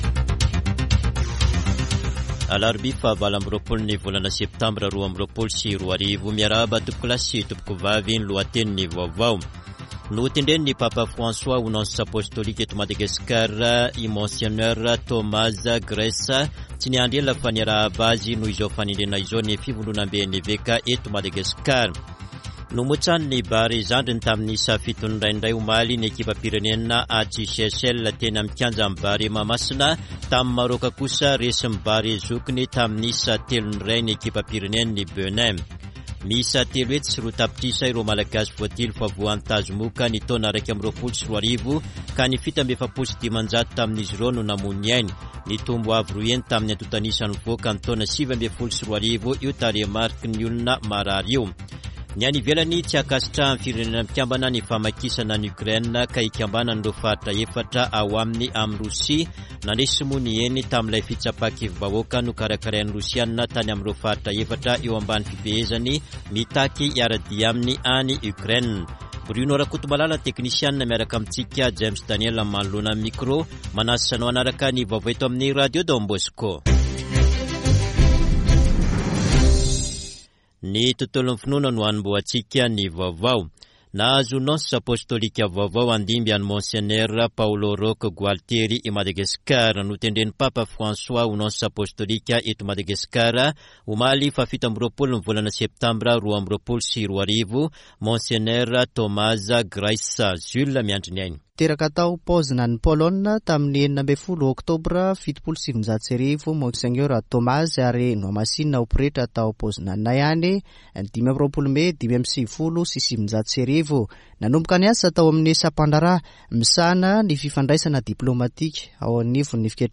[Vaovao maraina] Alarobia 28 septambra 2022